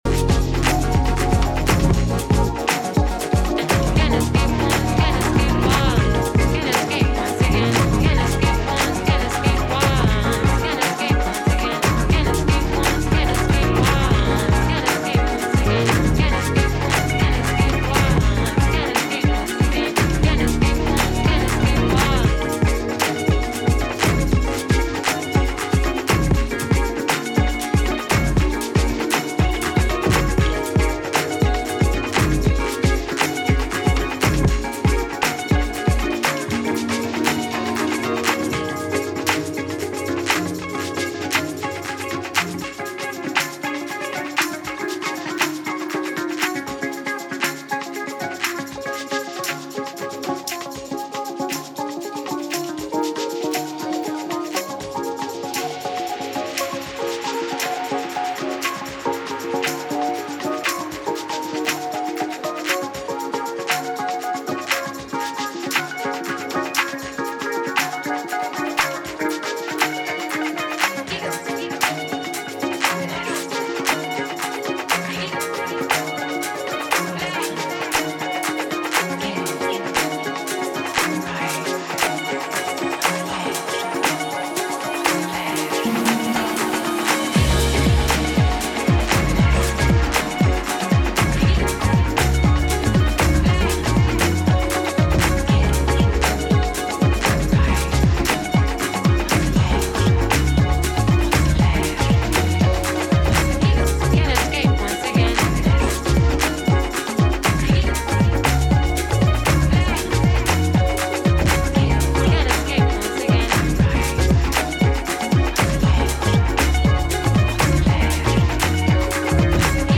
ブロークン・スタイルから四つ打ちへとシフトしていくそちらももちろん良し。